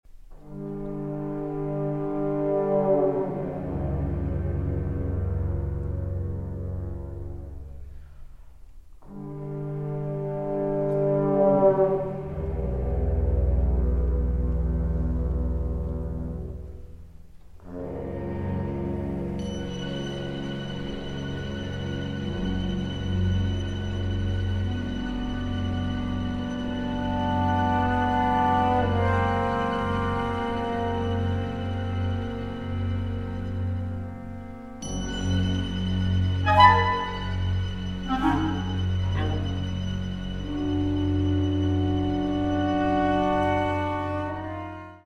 First Recordings made in the Presence of the Composer